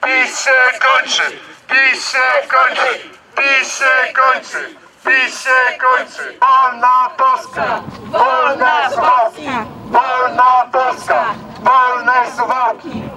Maszerując wznosili hasła: „PiS się kończy! Wolna Polska!”.